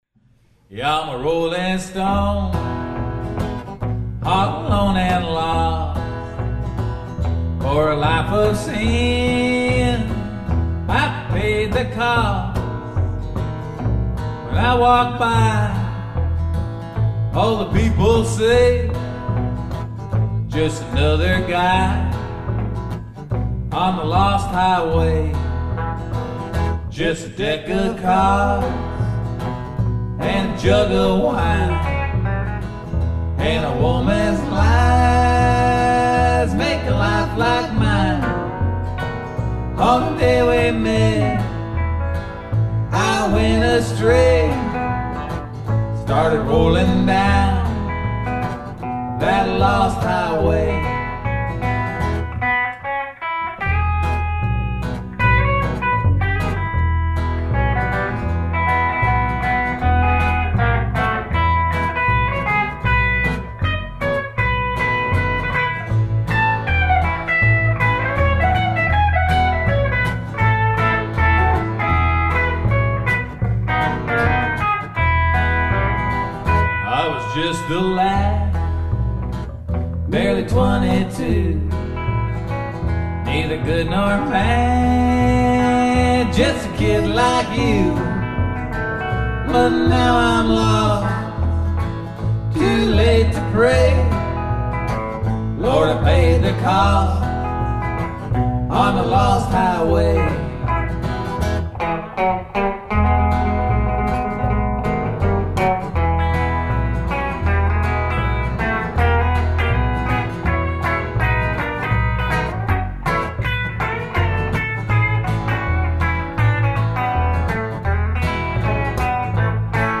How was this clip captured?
Recorded 3/14/06 at The John Boyle O'Reilly Club